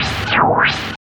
99 NOISE  -L.wav